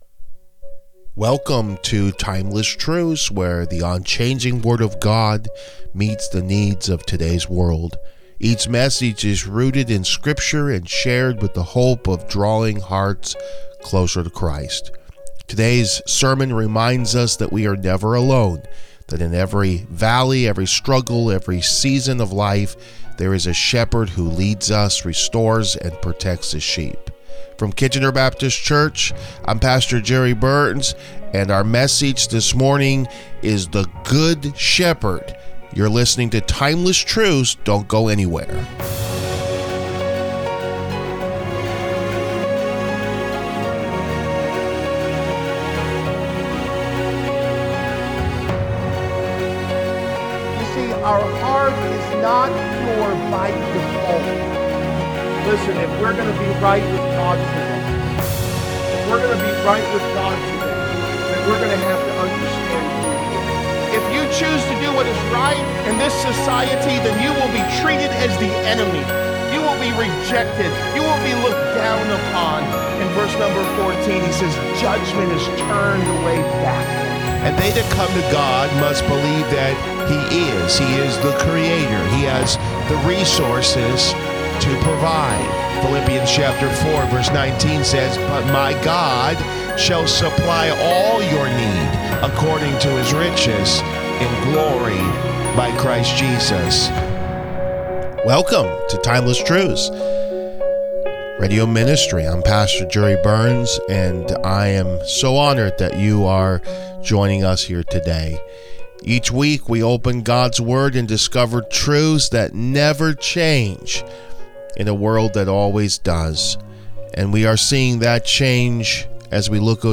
Timeless Truths Radio Ministry
🎵 Featured Song: “ Saved by the Blood” 📖 Scripture Reading: John 10:11–18